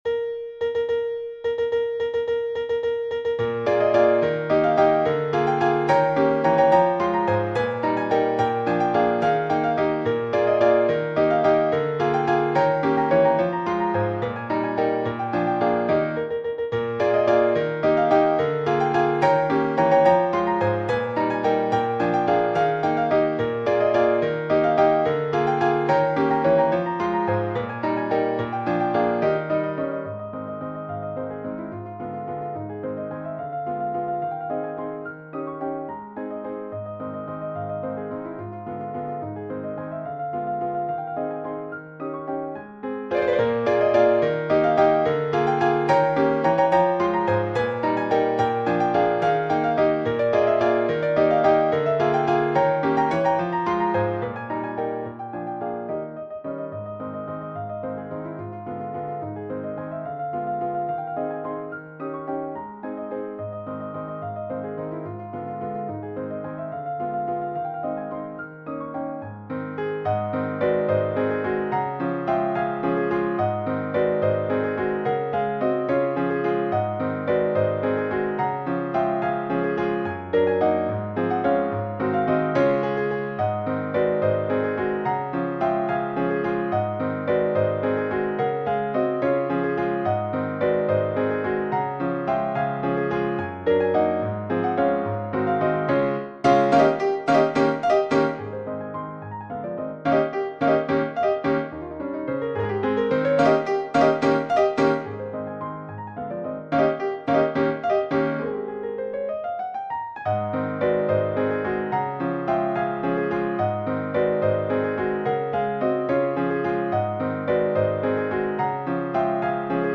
• 華やかで優雅な旋律: 明るく輝かしい雰囲気で、聴く人を魅了する美しい旋律が特徴です。
• 3拍子のワルツ: 軽快な3拍子のリズムで、舞踏会で踊られるワルツを彷彿とさせます。
Aの部分は華やかで、Bの部分は叙情的、Cの部分は中間部的な役割を果たしています。
• 中間部の変化: 中間部では雰囲気が変わり、叙情的な旋律が奏でられます。
• コーダ: 最後のコーダは、華やかで壮大なクライマックスを迎えます。
【楽譜】華麗なる大円舞曲 変ホ長調 作品18 / フレデリック・ショパン (ピアノソロ / 中〜上級) - Piascore 楽譜ストア